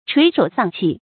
垂首喪氣 注音： ㄔㄨㄟˊ ㄕㄡˇ ㄙㄤˋ ㄑㄧˋ 讀音讀法： 意思解釋： 同「垂頭喪氣」。